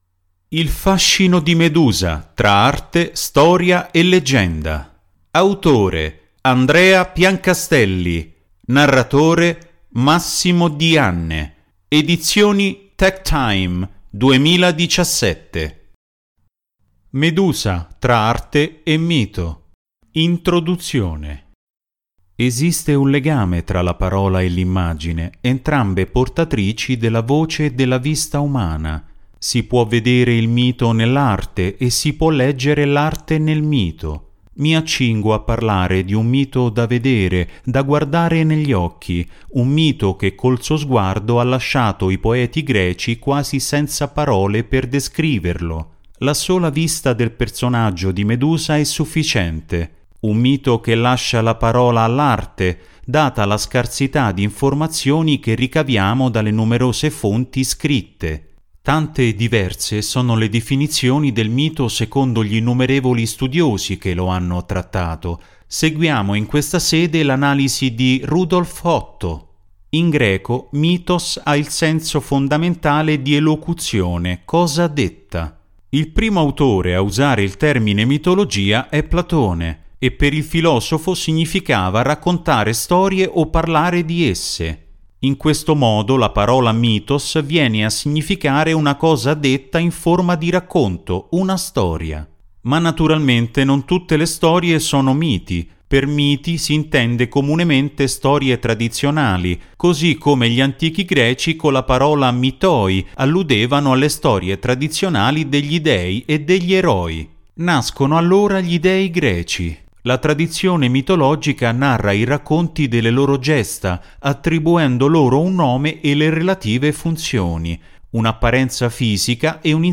IL FASCINO DI MEDUSA TRA ARTE STORIA E LEGGENDA - Audiobook